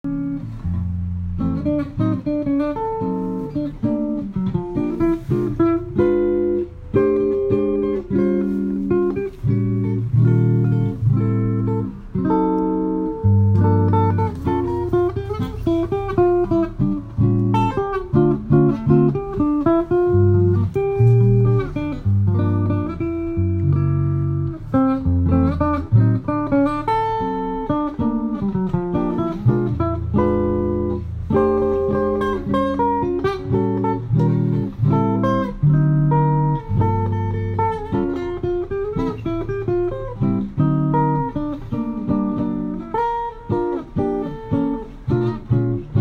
It has the responsiveness of an all carved archtop, with rich subtle tone, the comes to life when amplified while also reducing feedback.
This is a uniquely braced special edition Victor Baker Special Edition Archtop Guitar with great feedback resistance.